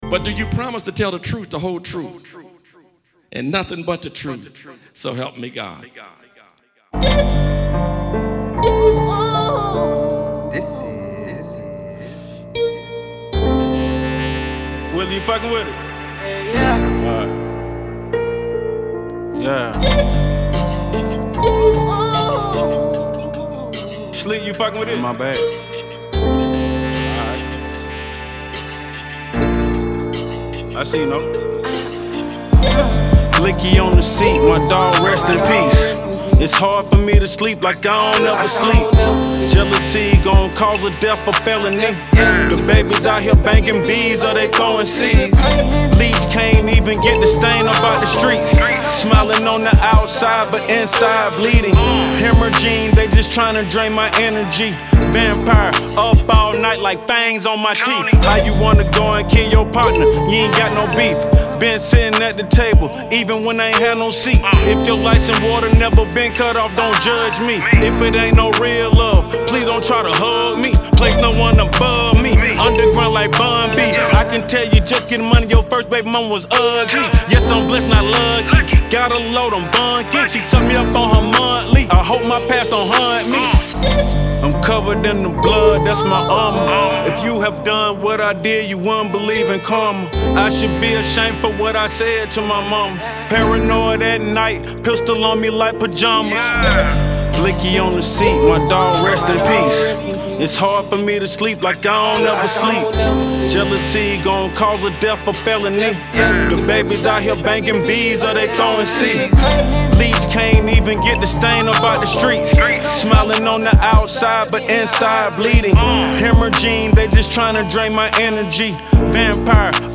American rapper and songwriter